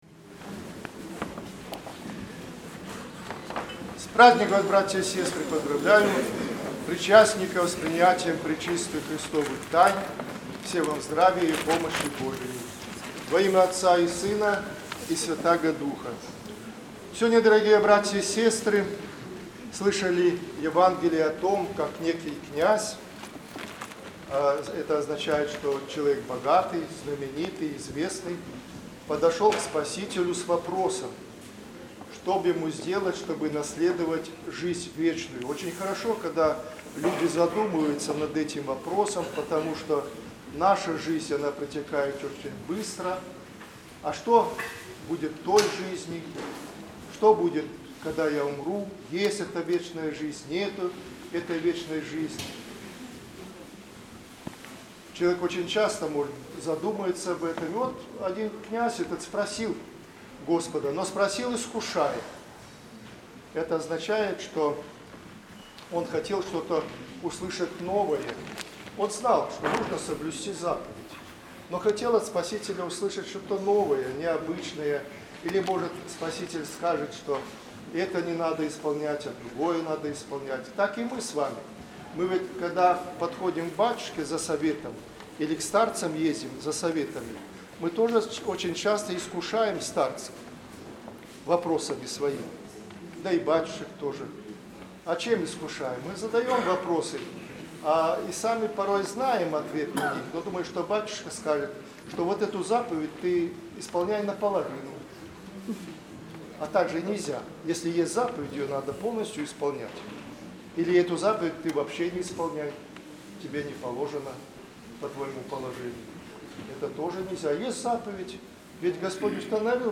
Божественная литургия была отслужена в Екатерининском храме.
Проповедь прот.